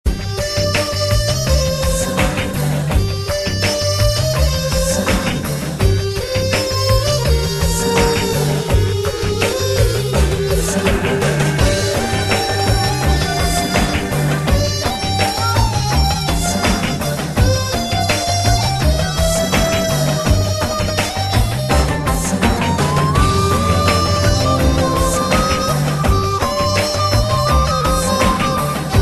sms , love , flute , heart , touching , sms love ,